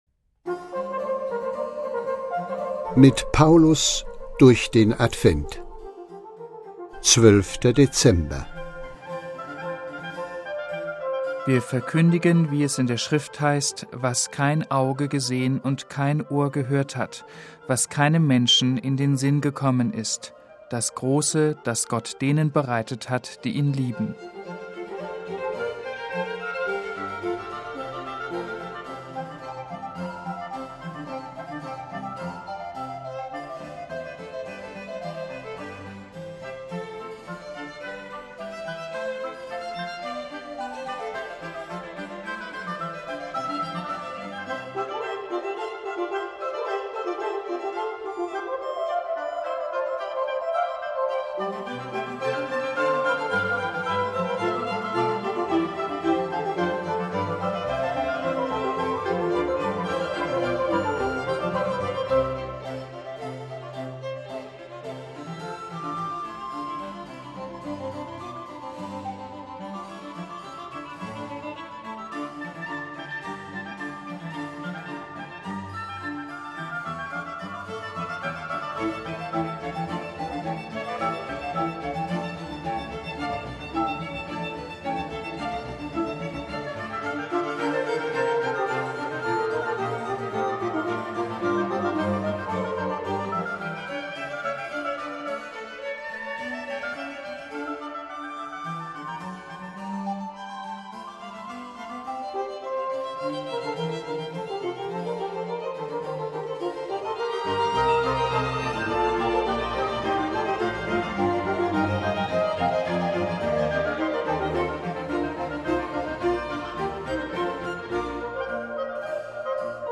„Mit Paulus durch den Advent“ ist das Motto dieses Audio-Adventskalenders, und an 24 Tagen lesen die Mitarbeiterinnen und Mitarbeiter einen ausgewählten Satz aus den Paulusbriefen.